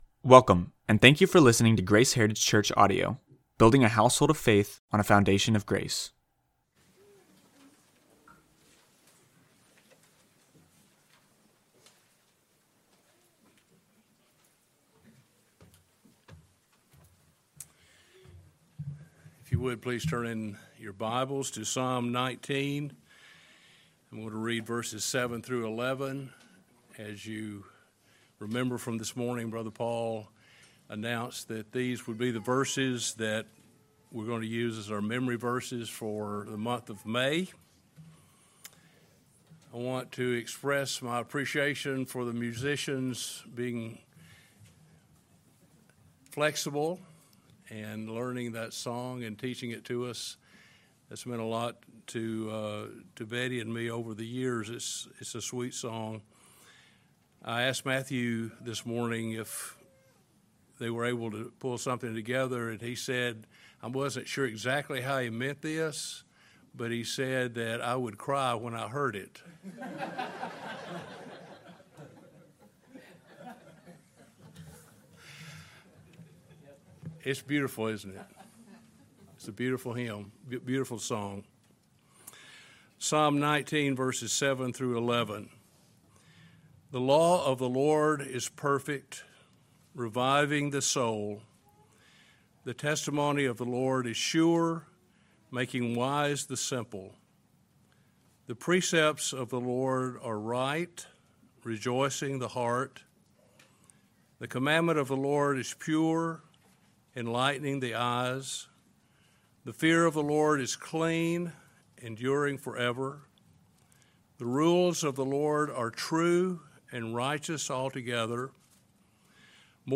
Evening Message